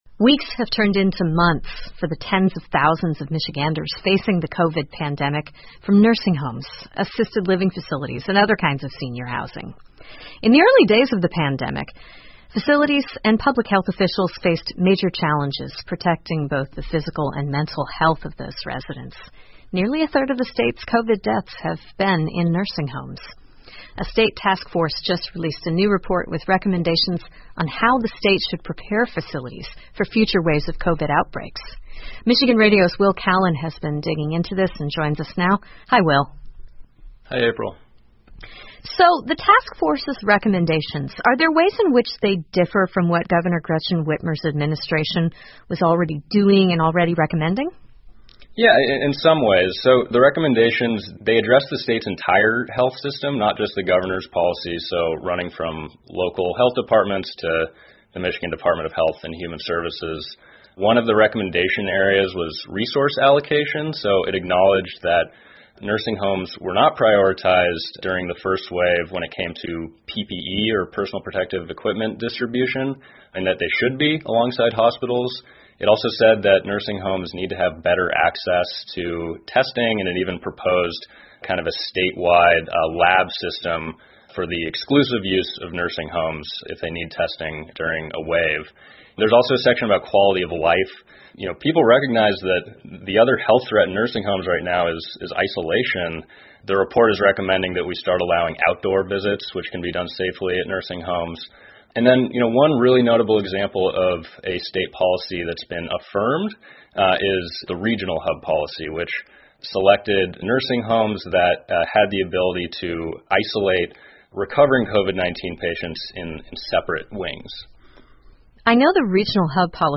密歇根新闻广播 疗养院专责小组向惠特默州长提出建议 听力文件下载—在线英语听力室